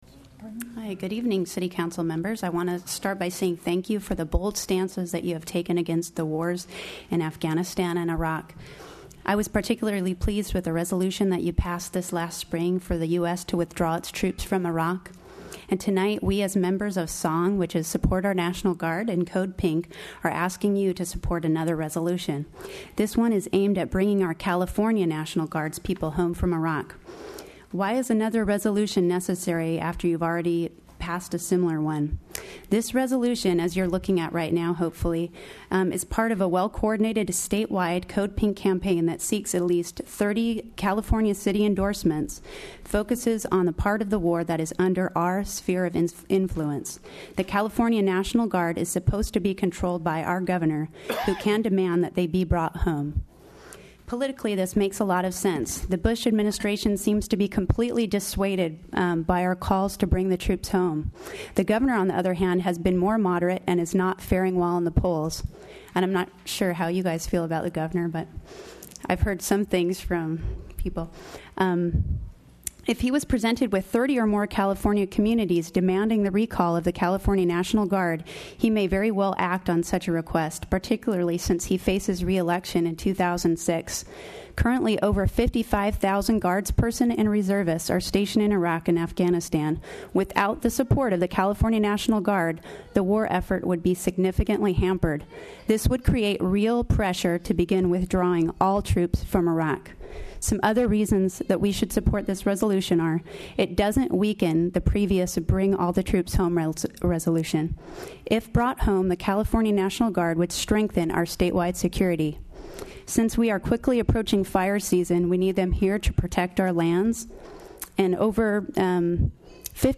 At the July 12 SC City Council meeting, a few of the SONG members spoke at oral communications.